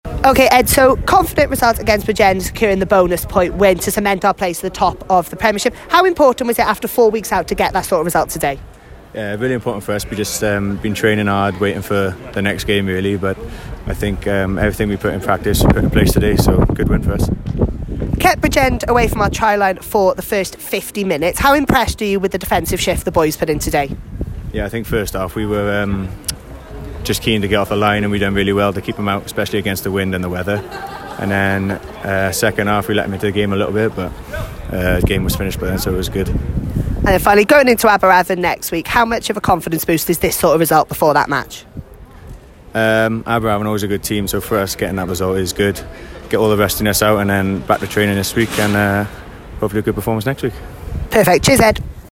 Post-Match Interviews.